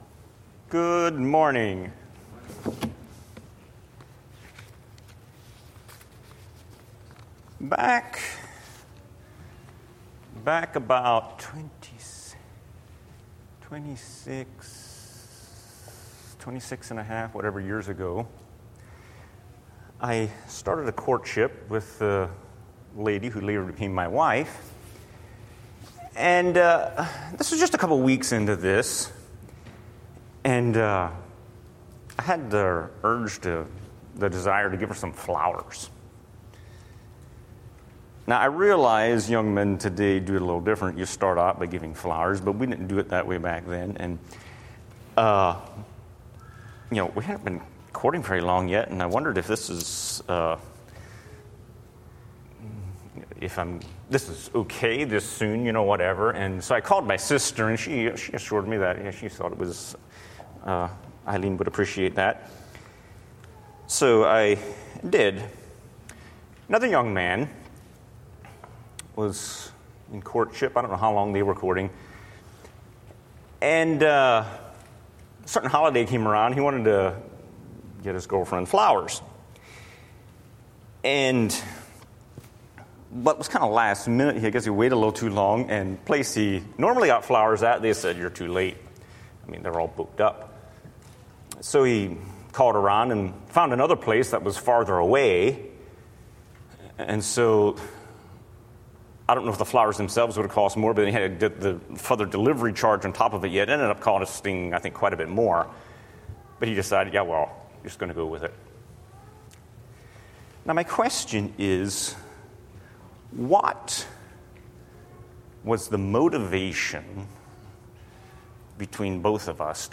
Living Hope | Sermon